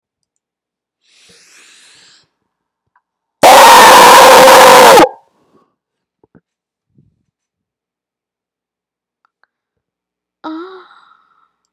Ahhhhhh - Botão de Efeito Sonoro